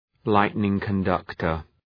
Shkrimi fonetik{‘laıtnıŋkən,dʌktər}